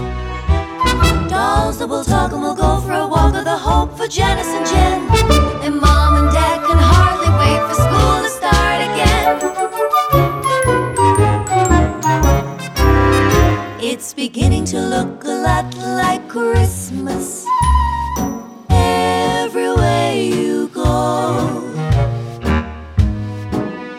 No Backing Vocals Christmas 2:36 Buy £1.50